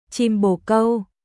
chim bồ câuチム ボー カウ
語尾の câu は英語の「cow」に近い音です。